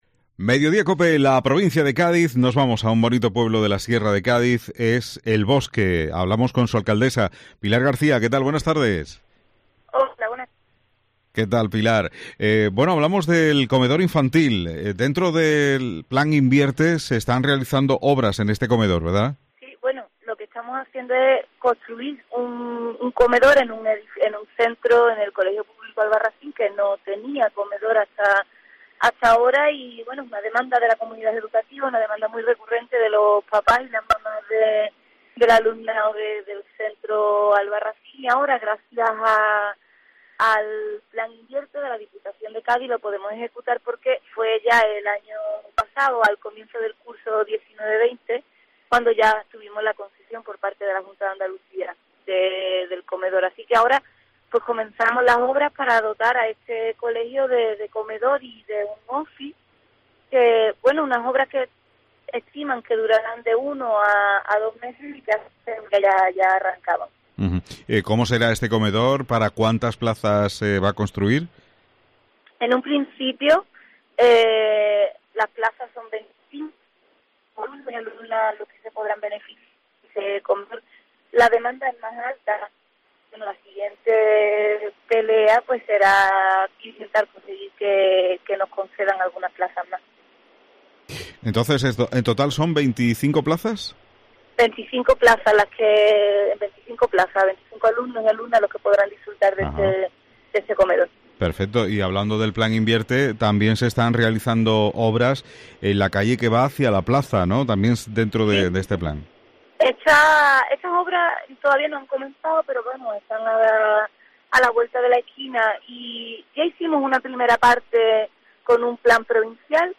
Pilar García, Alcaldesa de El Bosque habla del Plan Invierte y actuaciones contra la oruga procesionaria